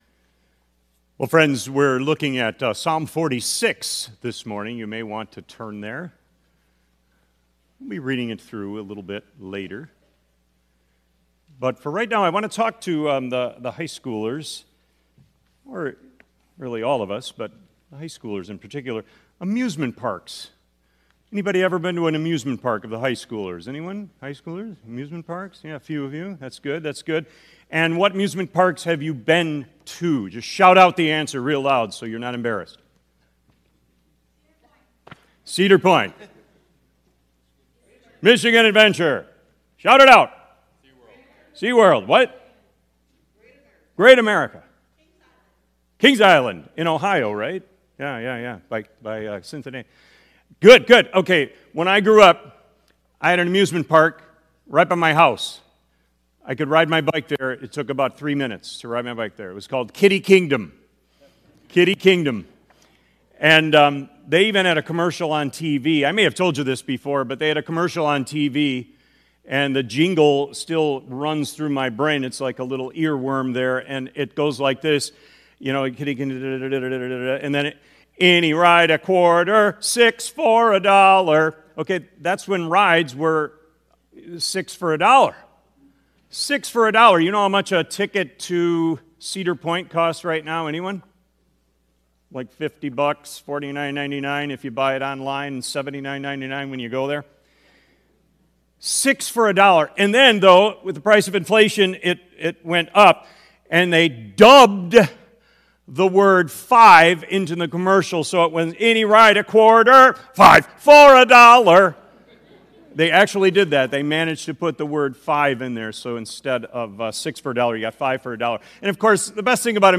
Sermon Recordings | Faith Community Christian Reformed Church
“Be Still” April 27 2025, A.M. Service